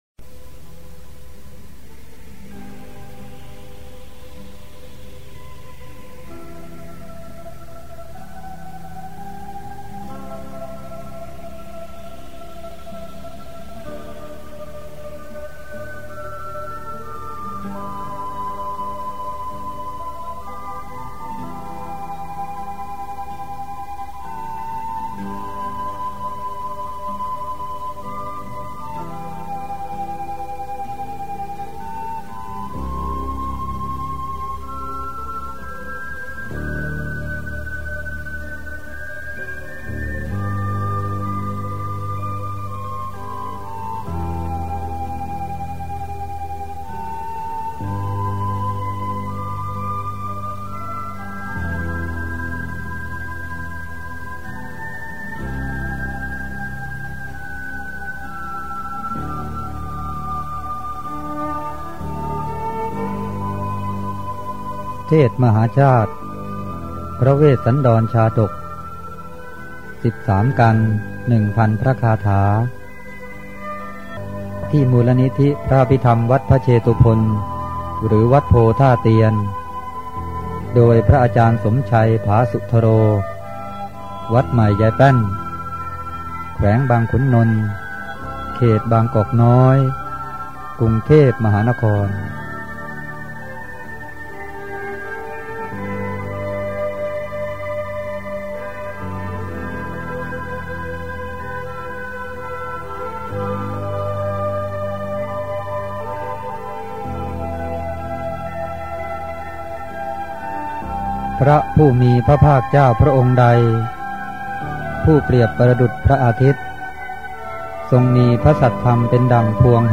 เทศน์มหาชาติ